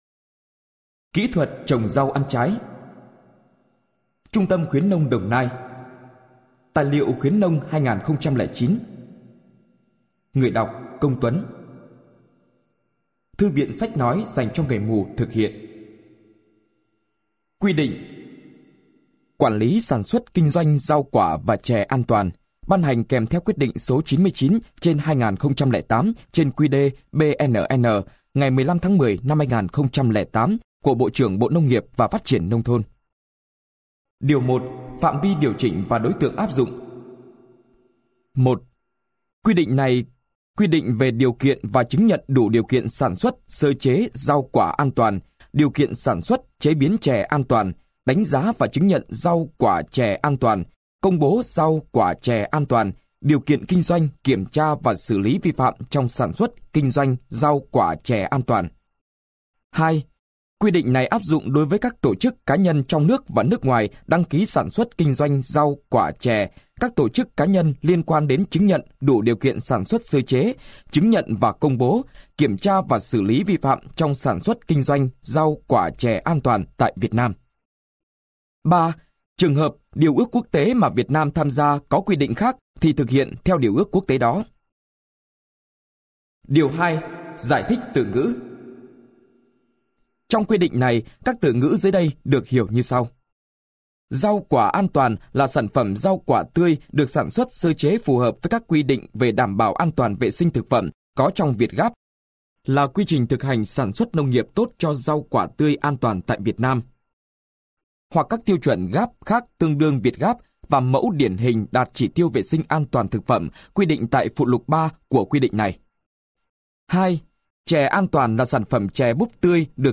Sách nói Kỹ Thuật Trồng Rau Ăn Trái - Sách Nói Online Hay